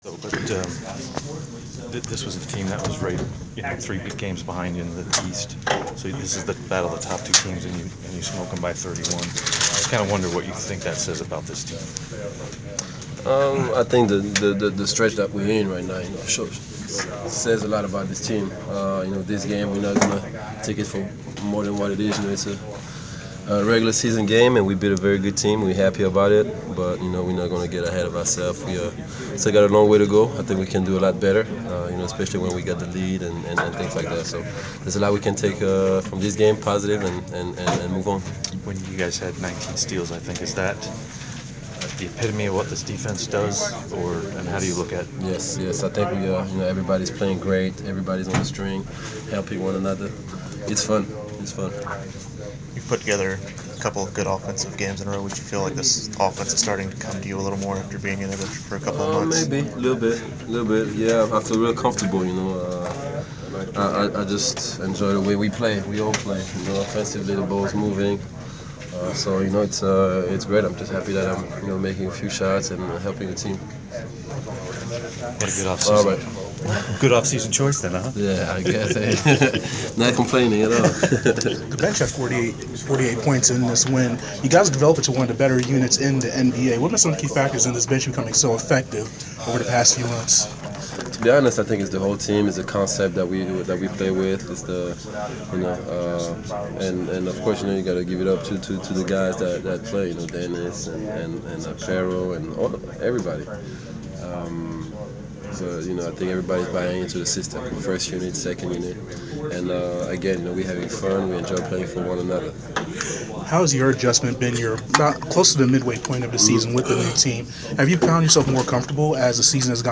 Inside the Inquirer: Postgame interview with Atlanta Hawks’ Thabo Sefolosha (1/11/15)